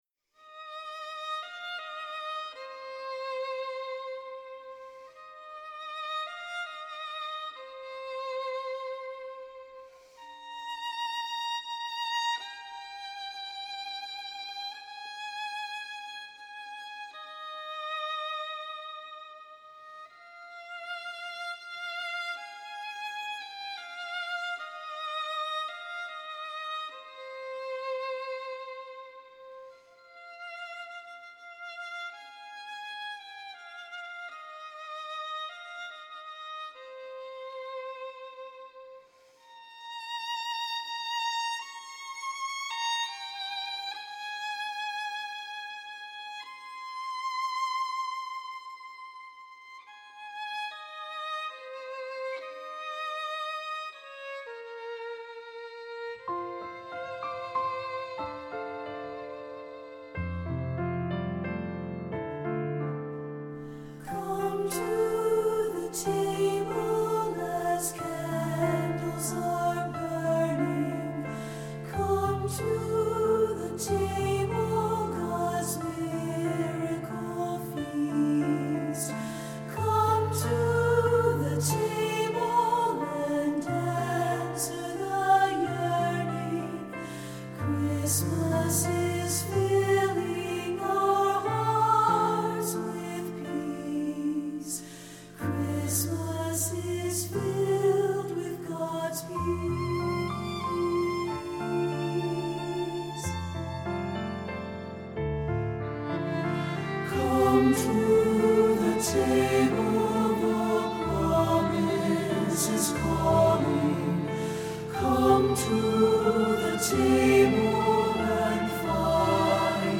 Voicing: SATB and Violin